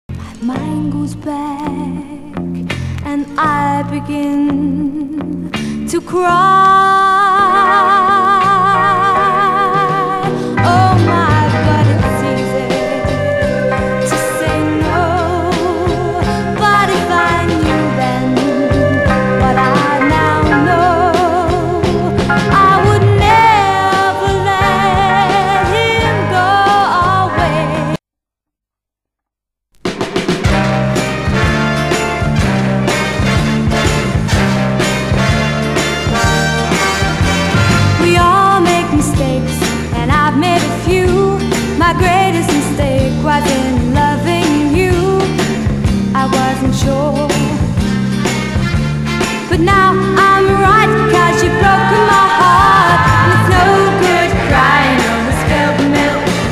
(税込￥16500)   UK GIRL